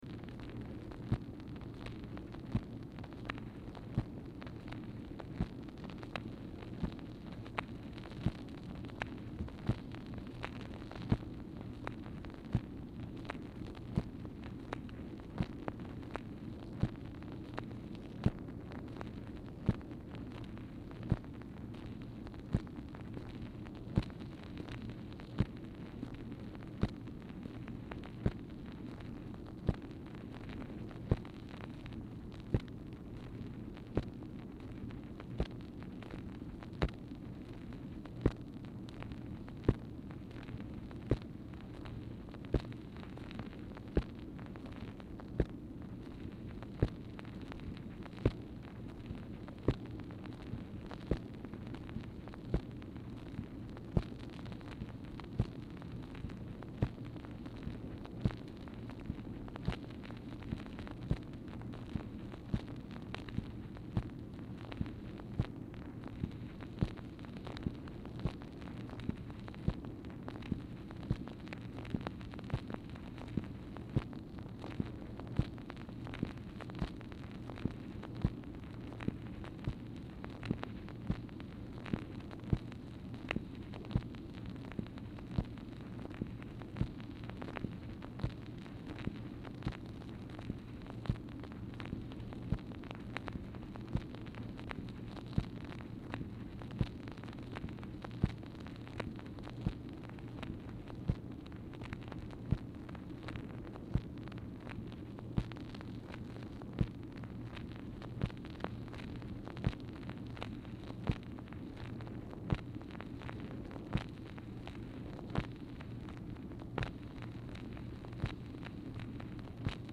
Telephone conversation # 3173, sound recording, MACHINE NOISE, 4/28/1964, time unknown | Discover LBJ
Format Dictation belt
Specific Item Type Telephone conversation